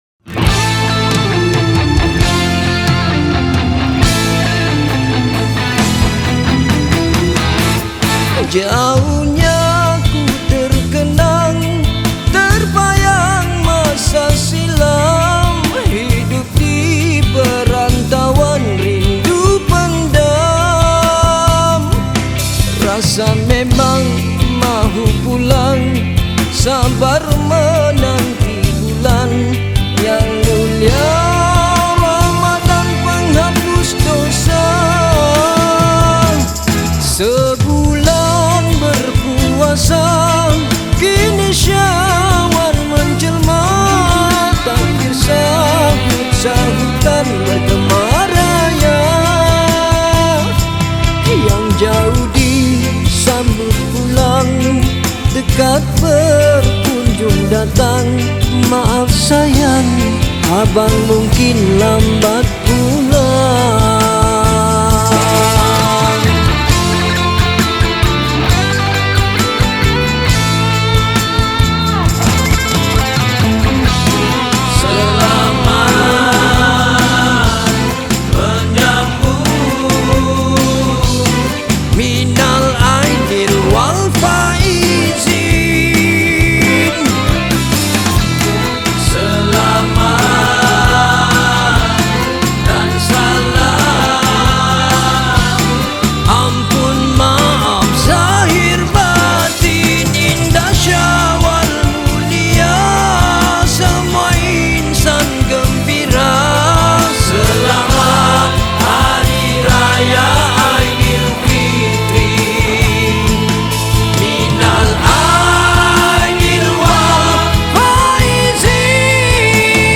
Lagu Hari Raya
Malay Song